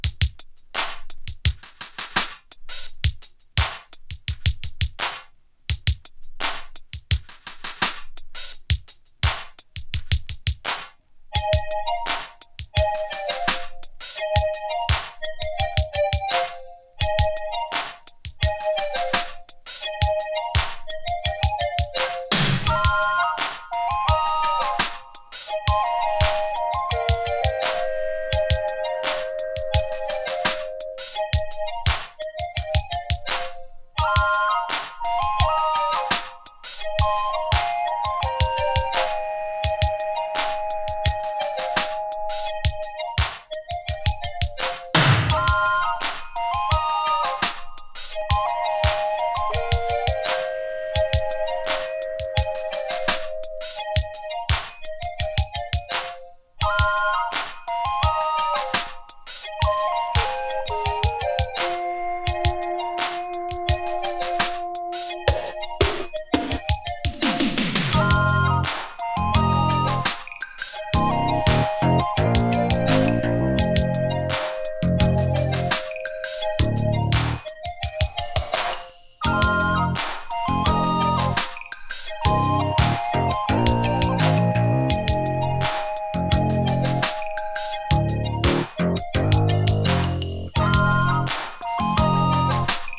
エレクトロニクスが多用されたポップ・フィーリング溢れ、フュージョンぽさは抑えられたサウンド。